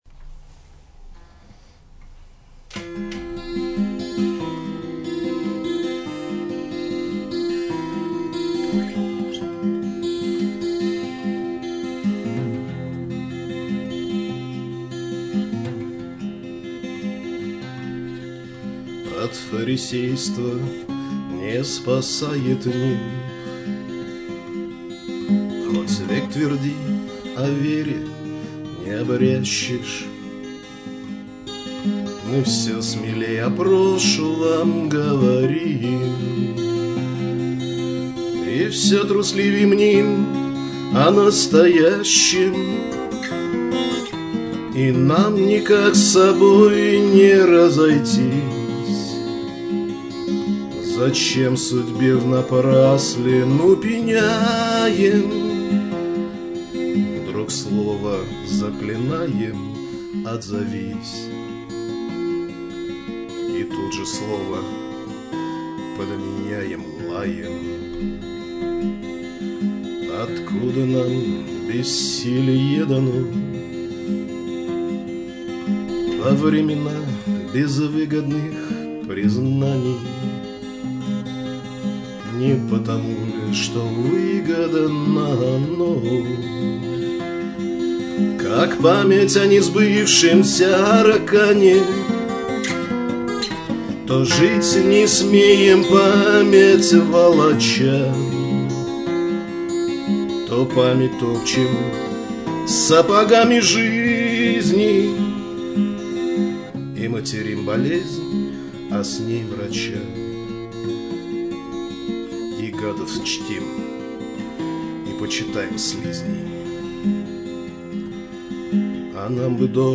vjashe.mp3 (1976k) Песенный вариант.